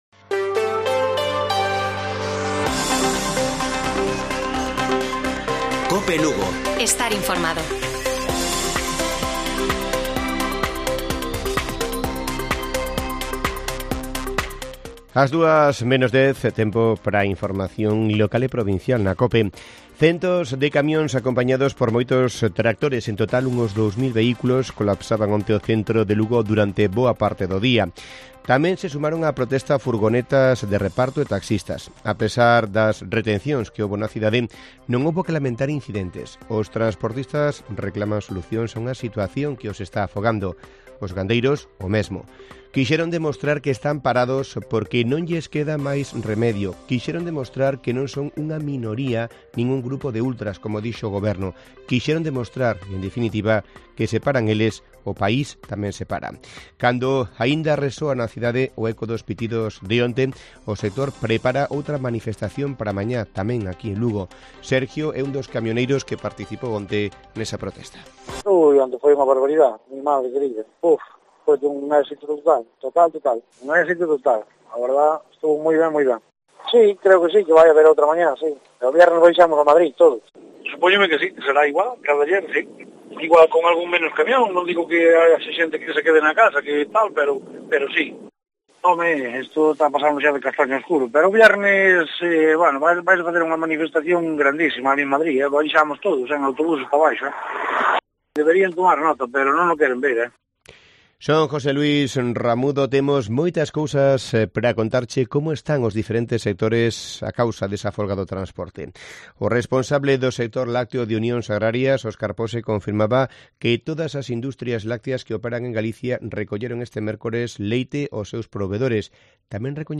Informativo Mediodía de Cope Lugo. 23 de marzo. 13:50 horas